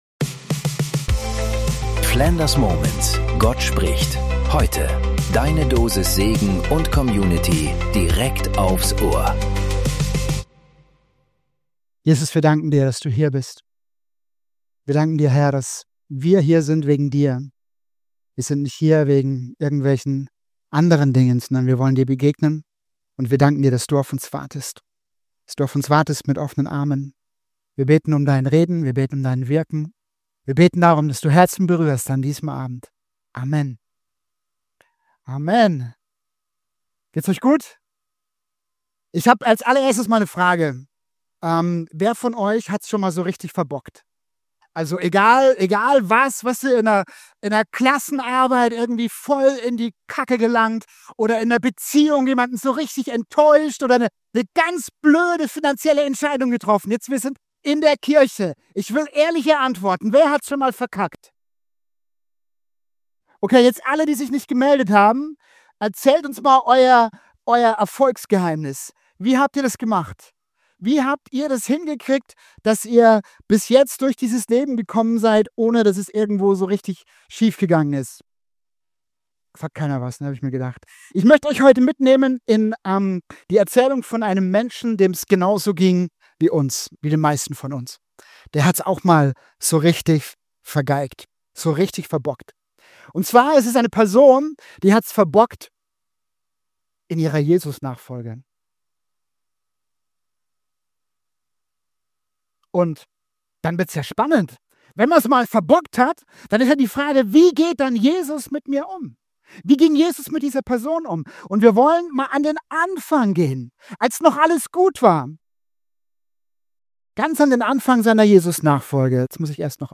Genau das ist die Geschichte von Petrus. Von der Begeisterung, ganz nah bei Jesus zu sein, über das große Scheitern, bis hin zur überraschenden Chance, neu anzufangen. Diese Predigt ist für alle, die sich von Gott entfernt fühlen, die sich selbst im Weg stehen oder denken, dass ihr Versagen alles kaputt gemacht hat.